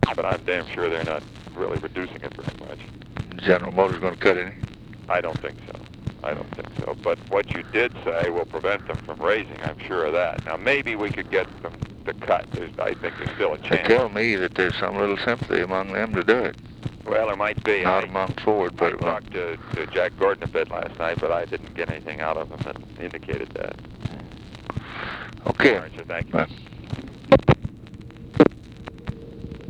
Conversation with ROBERT MCNAMARA, April 29, 1964
Secret White House Tapes